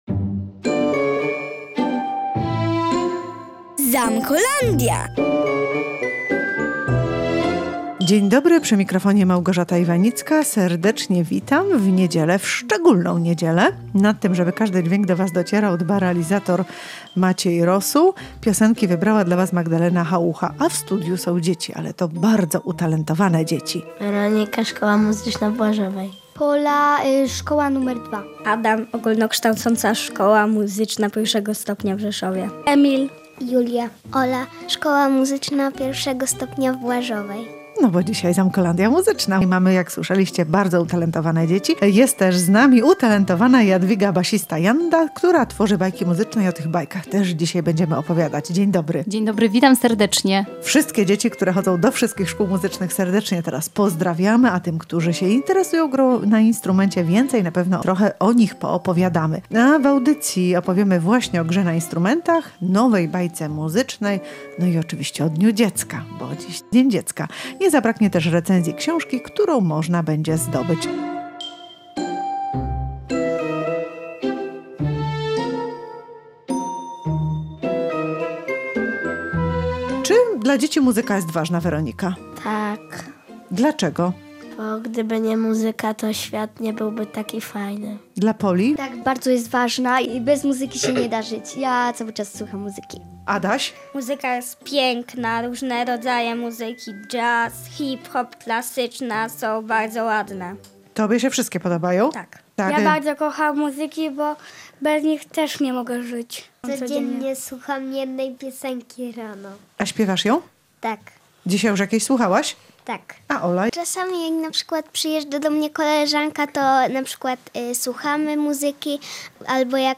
Odwiedzili nas między innymi młodzi uczniowie szkół muzycznych. Zagrali na instrumentach, na których uczą się grać. Były to klarnet, saksofon, skrzypce i tamburyn.